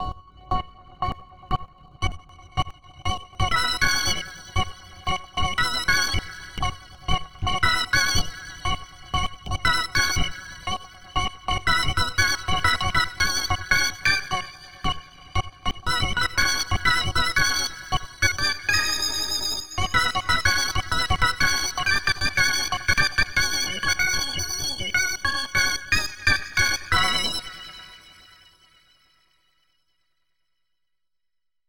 The net emulation is reasonable, it is distinctively a Hammond sound although
This is more for the sound than the quality of the playing.
Sample #1: first memory, preacher, Leslie, Chorus C3, bright enabled.